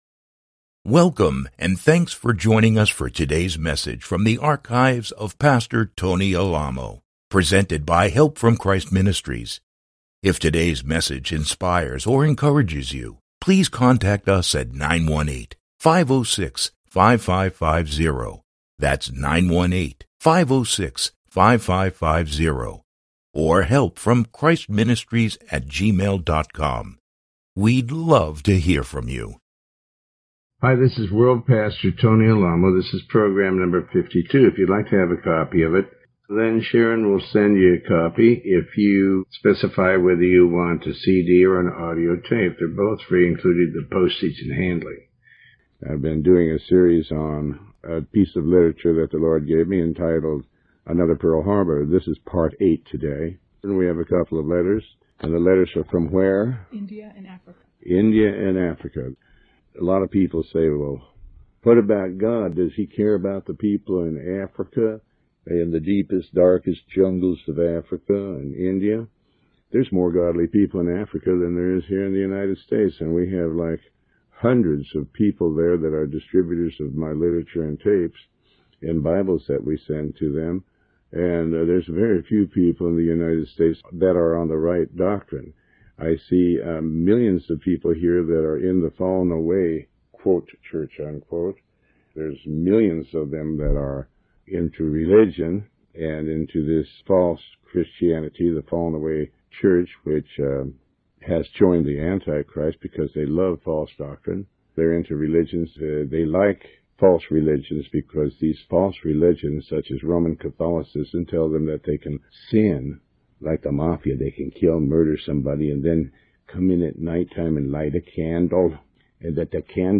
Sermon 52A